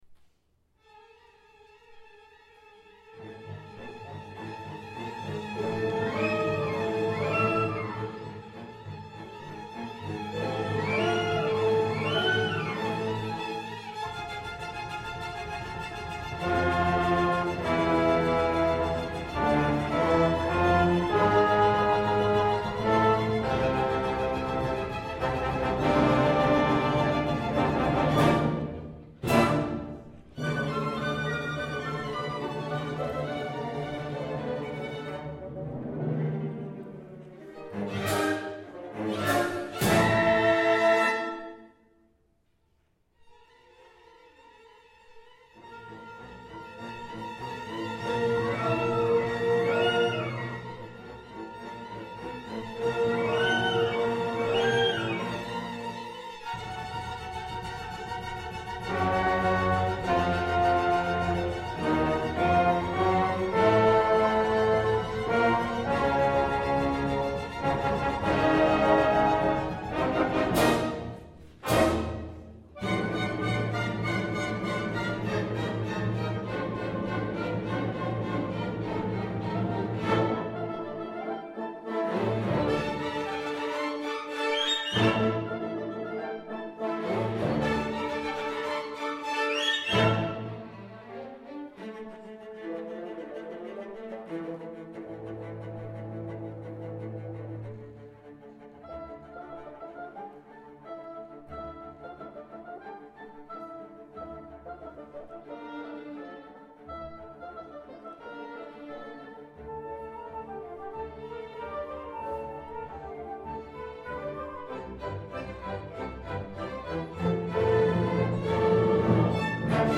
2017-08-12 Summer Pops – Oregon Pro Arte Chamber Orchestra
Made (even more) famous by Disney’s Fantasia, this is one of the most recognizable classical orchestral works today. It is a tone poem by Modest Mussorgsky, detailing a witches’ sabbath occurring on St. John’s Eve (or Kupala Night) on a mountain near Kiev. The piece evokes images of “profane rituals” and conjured evil spirits, and ends as dawn breaks and the hordes vanish. This was a piece from our August 2017 pops concert – I had a lot of fun playing this.